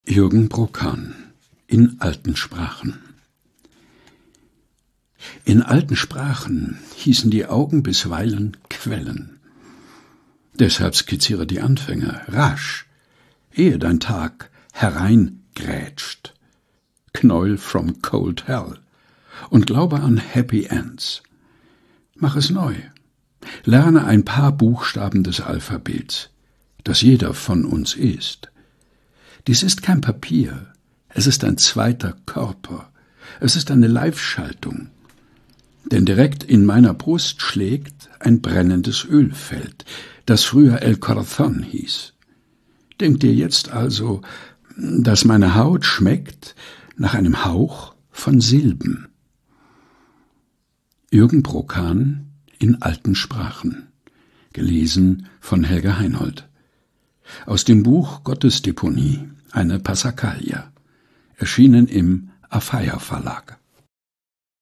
Texte zum Mutmachen und Nachdenken - vorgelesen